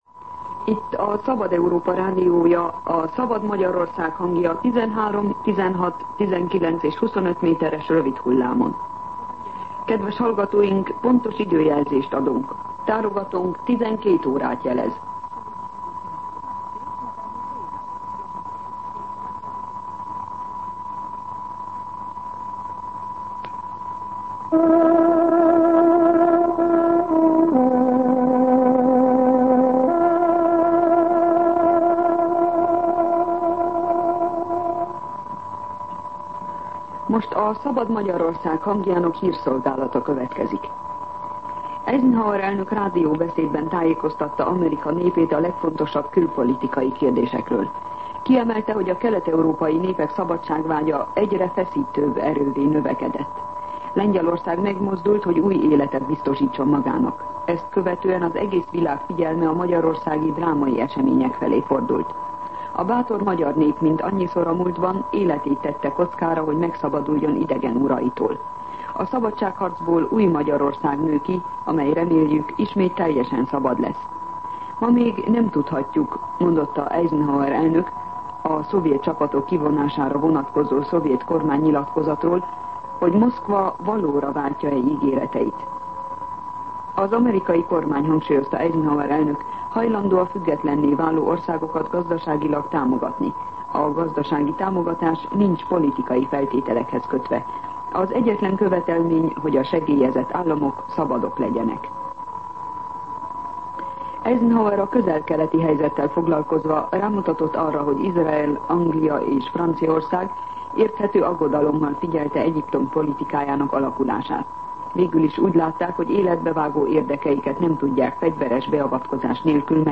12:00 óra. Hírszolgálat